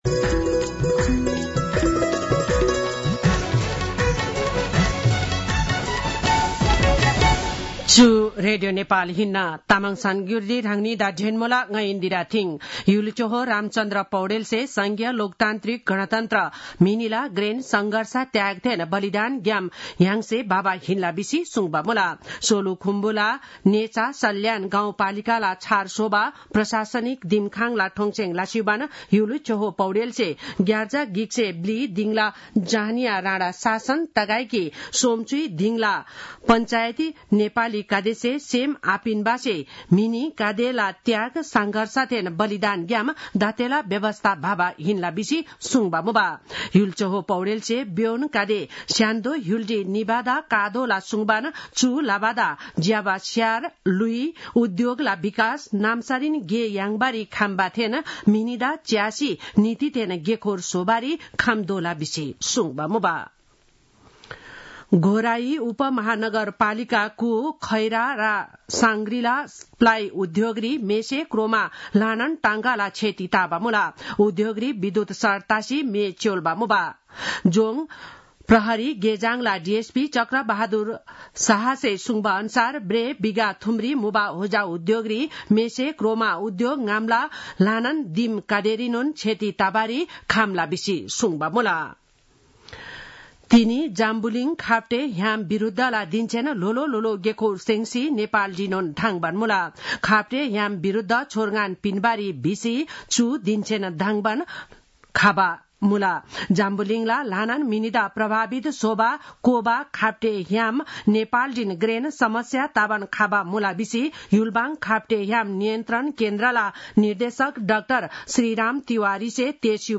तामाङ भाषाको समाचार : ११ चैत , २०८१